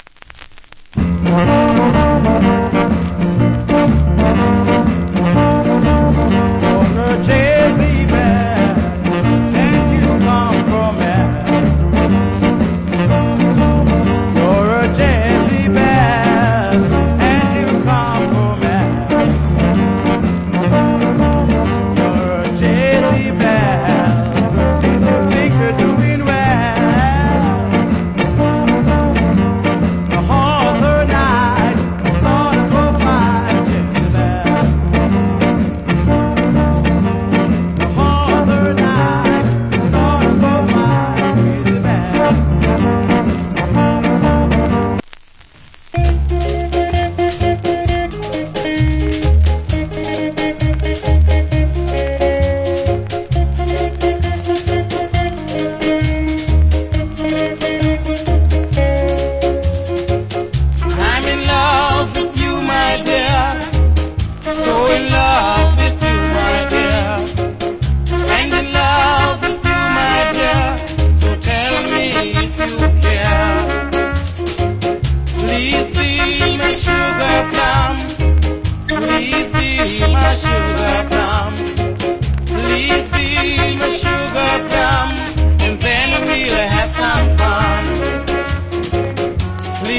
Nice Jamaican R&B Vocal.